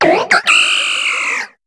Cri de Sucreine dans Pokémon HOME.